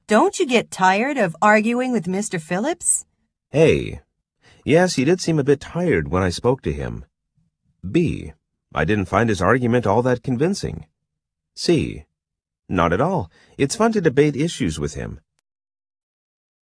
TOEIC音声問題 のアイコンをクリックすると、問い掛けや発言に続いて、それに対する応答が3つ流れます。